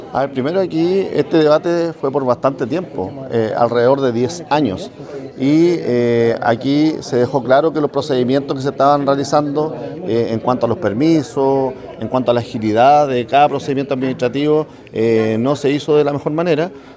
Fue el alcalde de Concepción, Héctor Muñoz, quien respondió a los cuestionamientos de algunos concejales que dijeron que la votación fue más política que técnica.